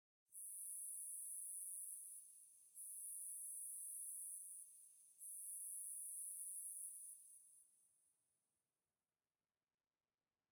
firefly_bush9.ogg